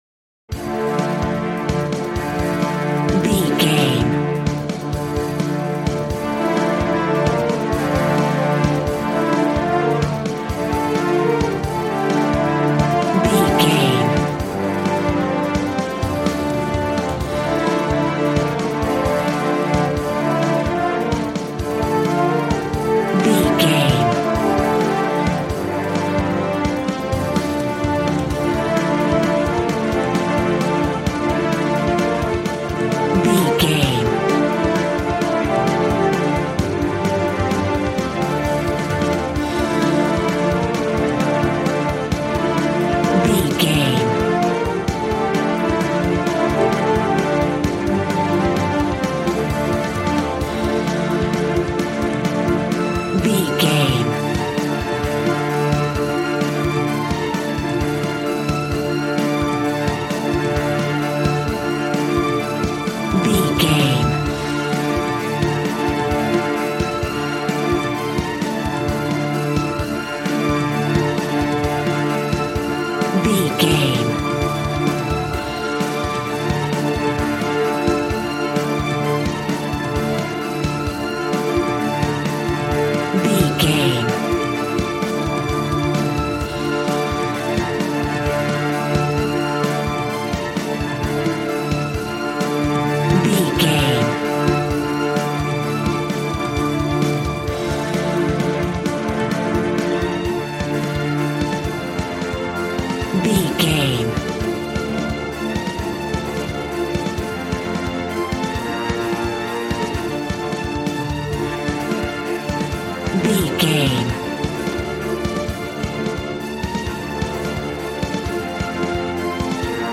Aeolian/Minor
D
dramatic
strings
violin
brass